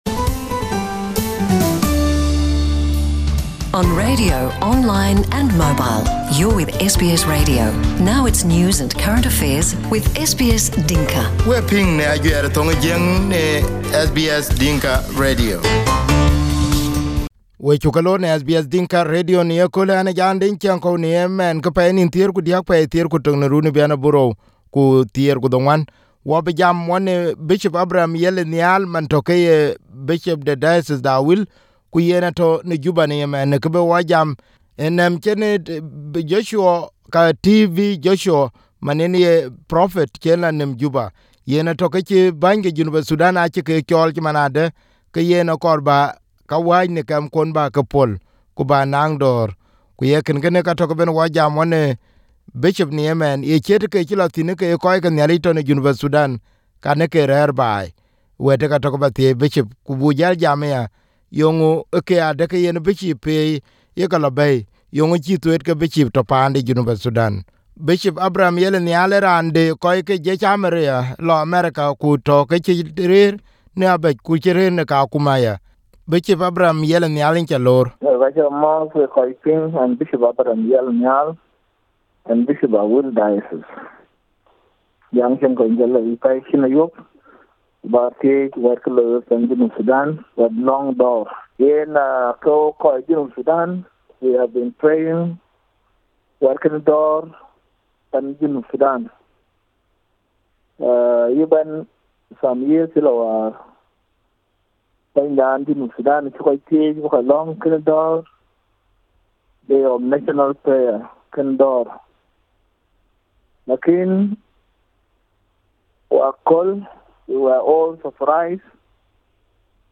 Bishop Abraham Yel was interviewed this morning on SBS Dinka radio.